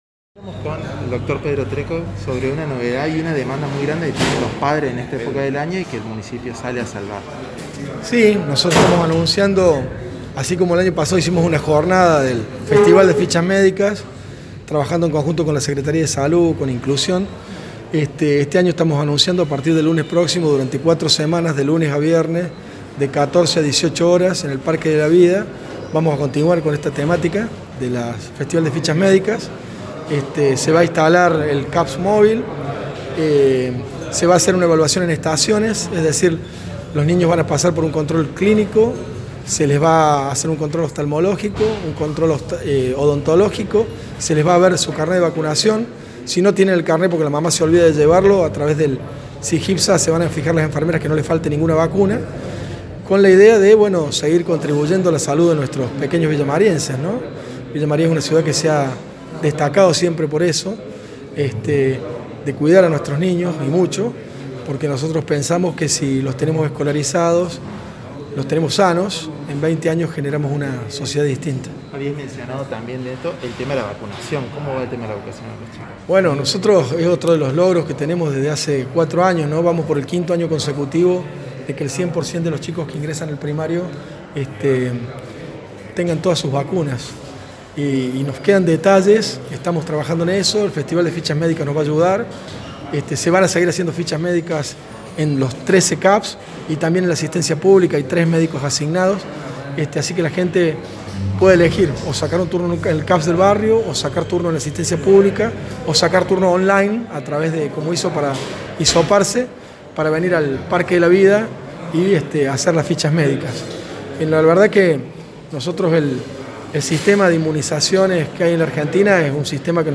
Escuche la palabra del Dr. Pedro Trecco, responsable de la Cartera de Salud Pública de la Municipalidad: